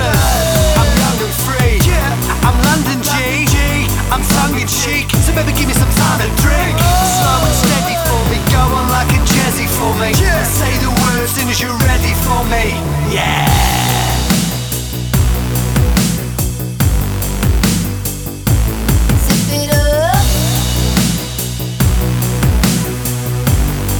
For Solo Female R'n'B / Hip Hop 3:55 Buy £1.50